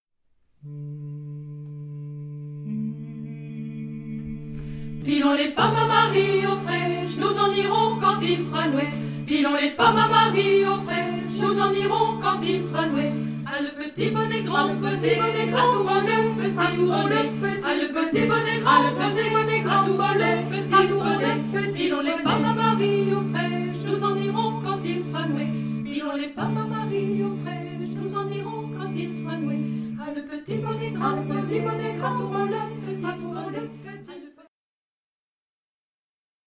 On pourra y ajouter des bourdons et une deuxième voix très simple tirée de la première, avec un départ décalé